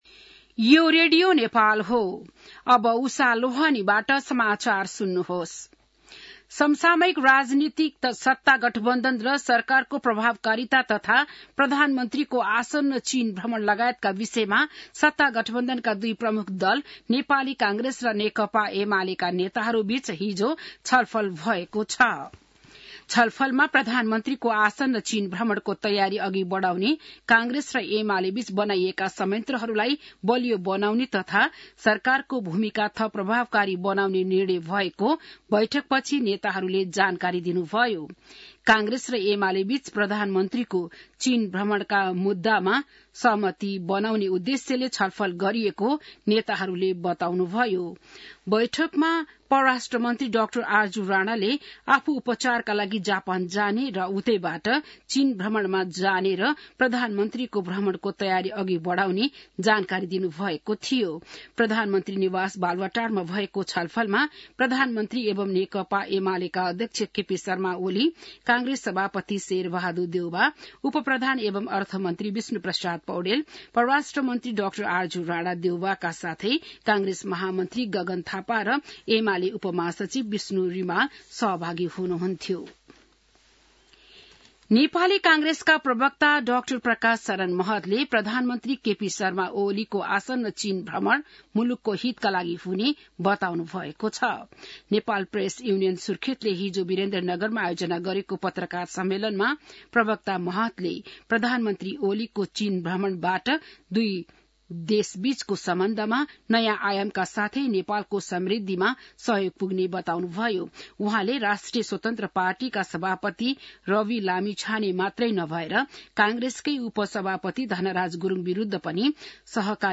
बिहान १० बजेको नेपाली समाचार : ६ मंसिर , २०८१